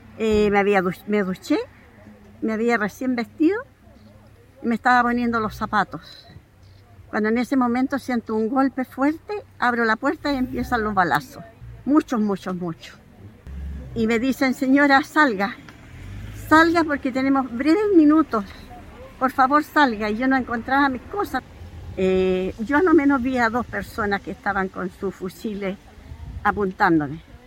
Los testimonios recogidos en el lugar por Radio Bío Bío son desoladores y dan cuenta de la acción coordinada, violenta e impune de hombres fuertemente armados.